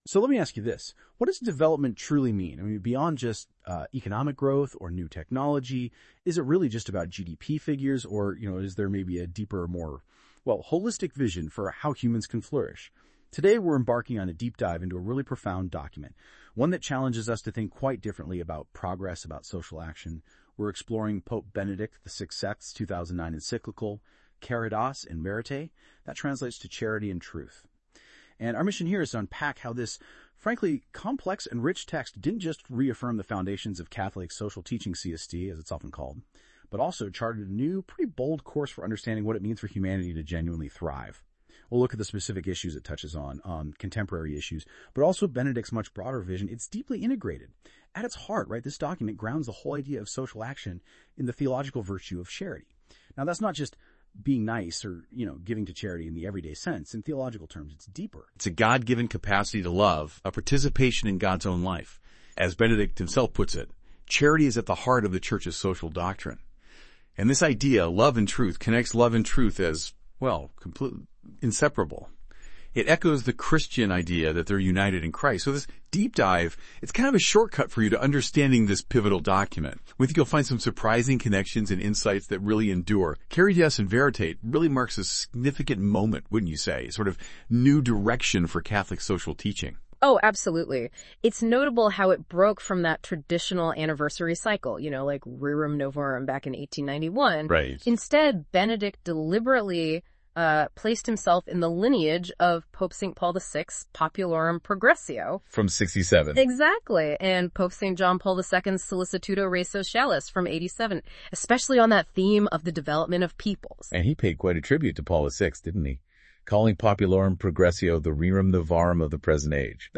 Listen to this AI-powered Caritas in Veritate summary.